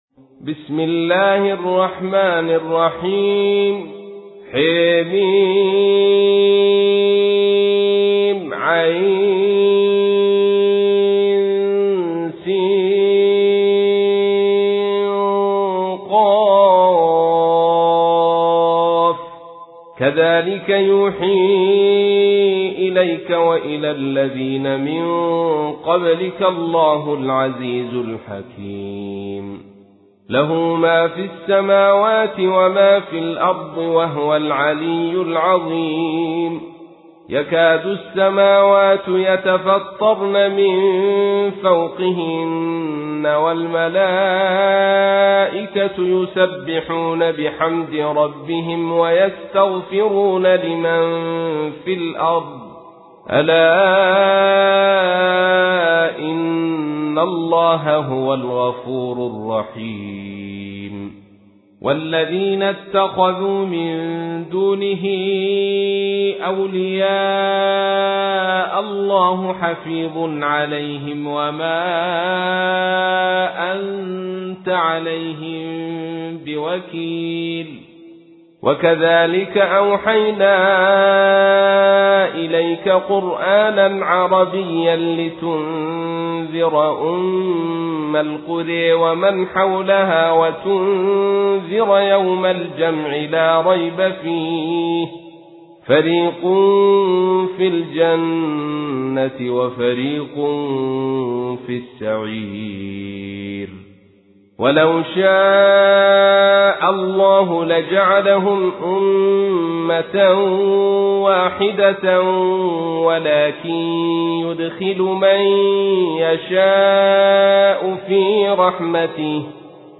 تحميل : 42. سورة الشورى / القارئ عبد الرشيد صوفي / القرآن الكريم / موقع يا حسين